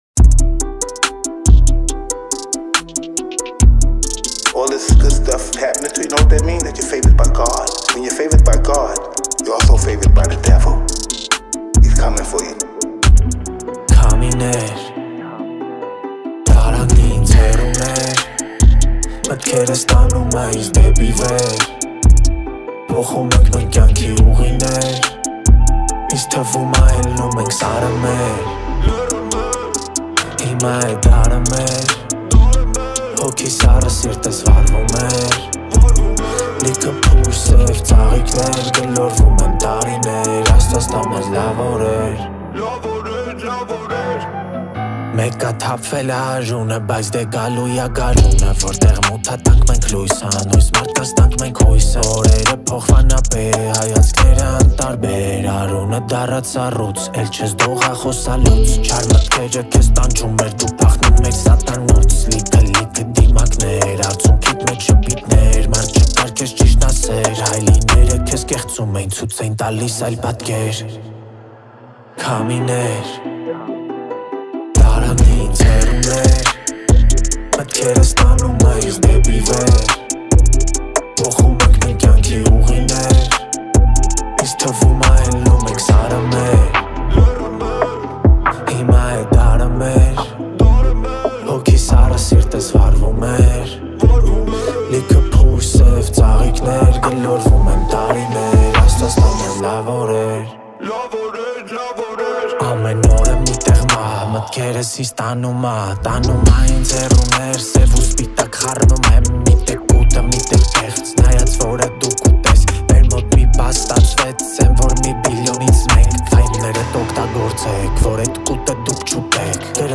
Армянский реп
Армянская музыка